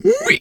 Animal_Impersonations
pig_2_hog_single_02.wav